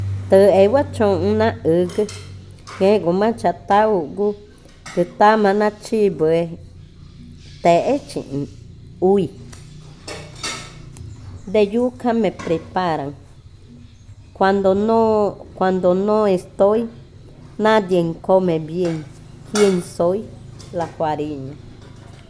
Adivinanza 24. Fariña
Cushillococha